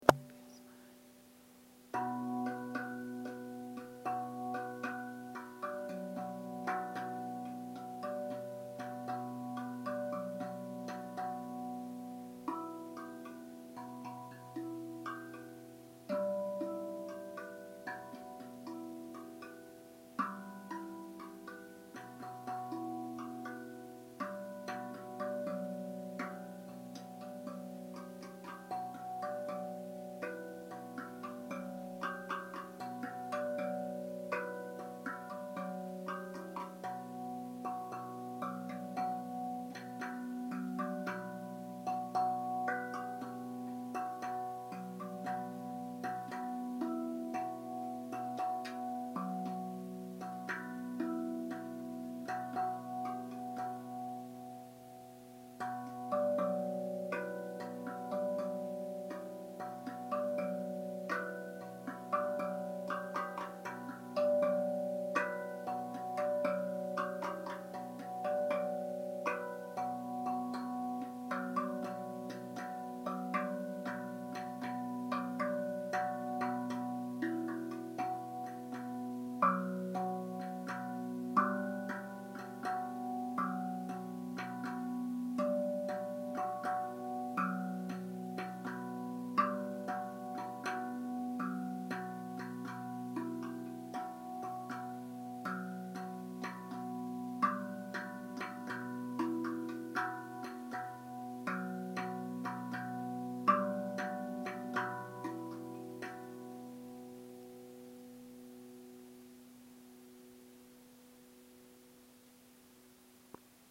Rav Vast Drum - Do Yoga
Seit Sommer 2022 durfte ich die Rav Vast Drum kennenlernen und kann nun die Tiefenentspannungen musikalisch untermalen.
Rav-Vast-Drum.mp3